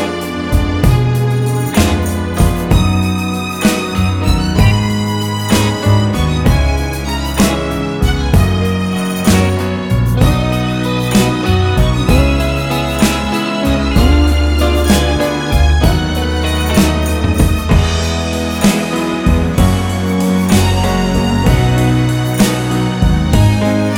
no Backing Vocals Jazz / Swing 3:41 Buy £1.50